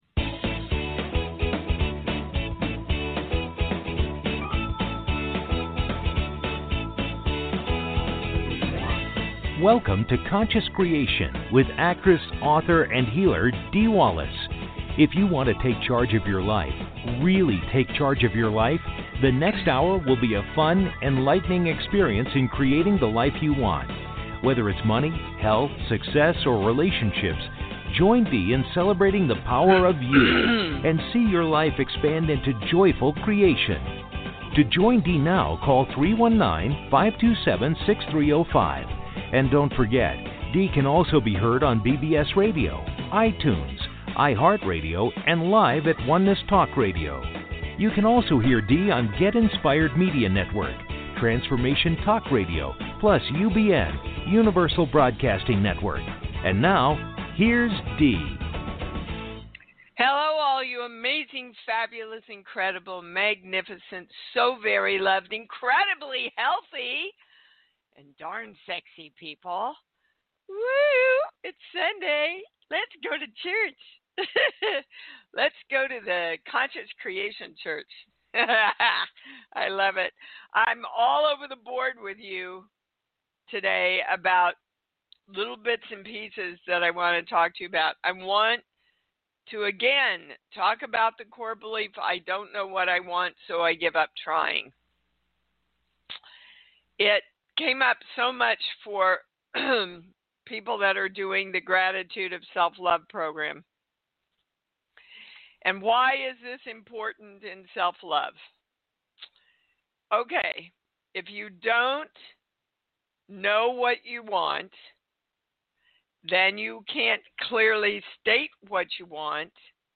Talk Show Episode, Audio Podcast, Conscious Creation and with Dee Wallace on , show guests , about conscious creation,Dee Wallace,I am Dee Wallace, categorized as Health & Lifestyle,Politics & Government,Society and Culture,TV & Film,Variety